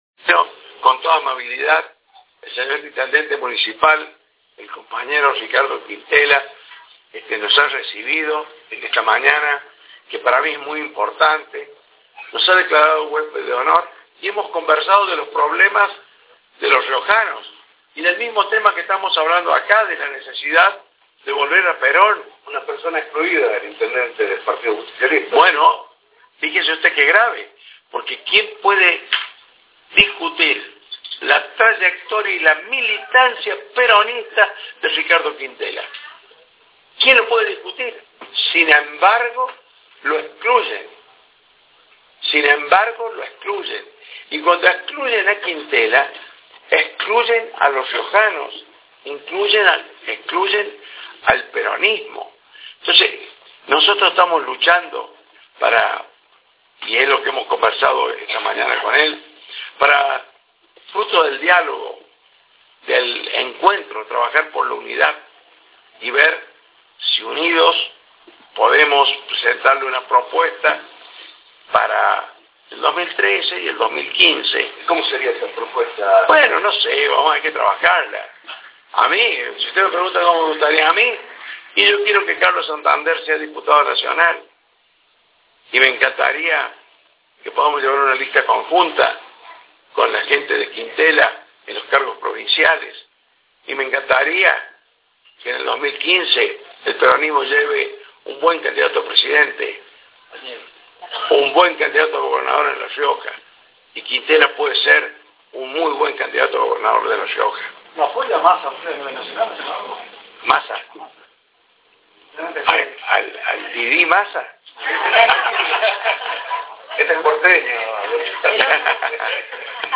Adolfo Rodríguez Saá, senador nacional por San Luis
Luego el lelgislador nacional ofreció una conferencia de prensa en el local partidario, frente al Concejo Deliberante.